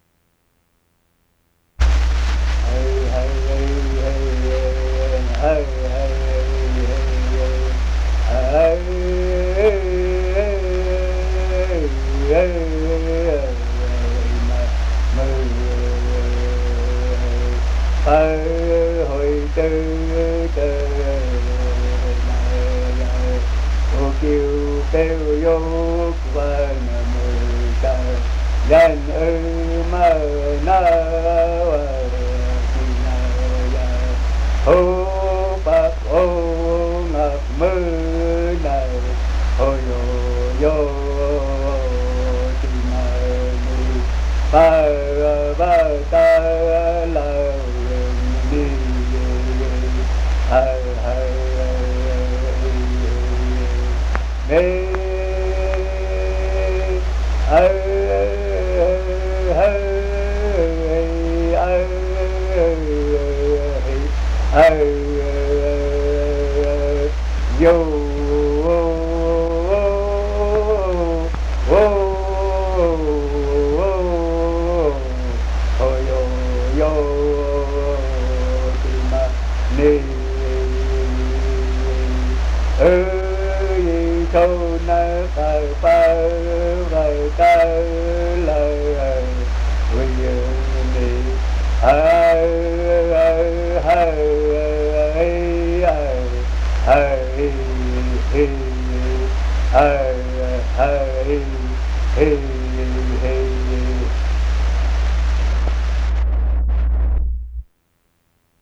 Sound recordings Music (performing arts genre)